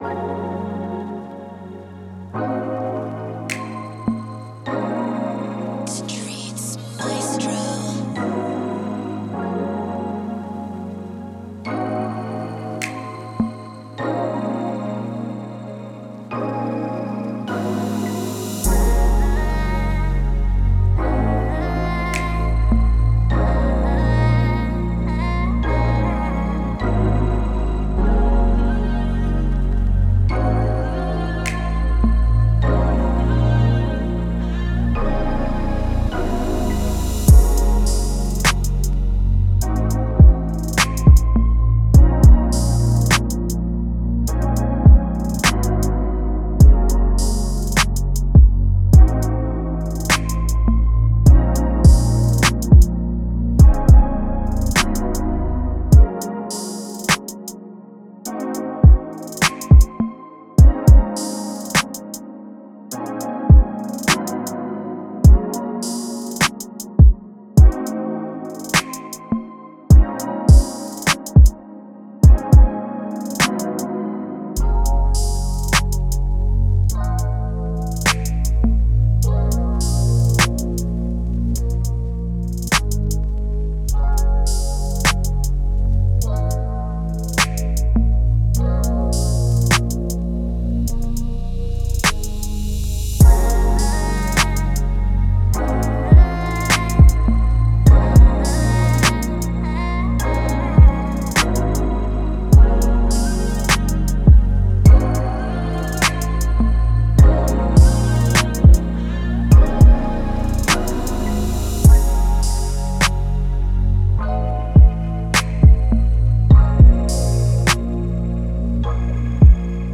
Laid Back R&B Type Beat
Moods: laid back, intimate, mellow
Genre: R&B
Tempo: 103